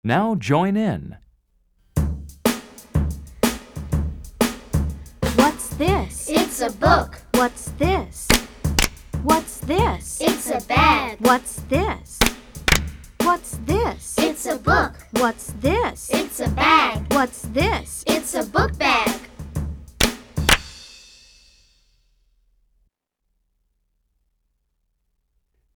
Estem treballant aquest “chant”!